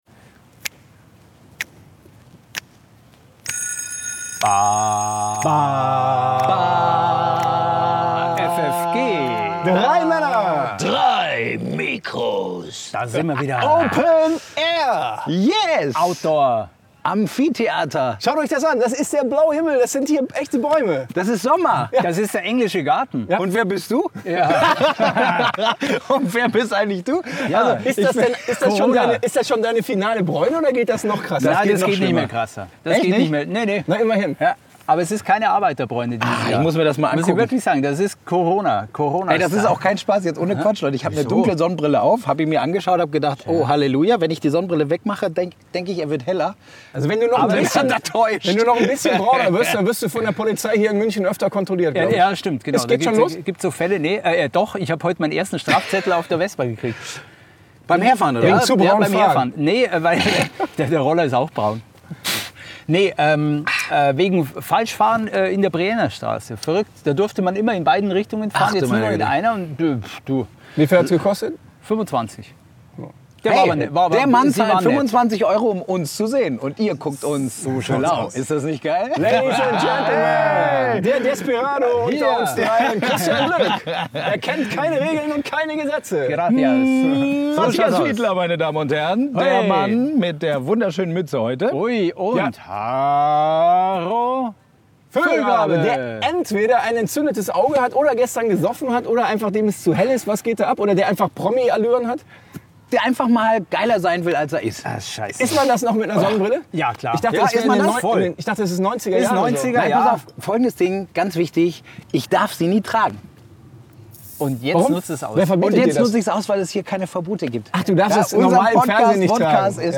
Diesmal haben FFG ihre Podcast-Folge open air aufgenommen!
Im Amphitheater im Nordteil des Englischen Garten!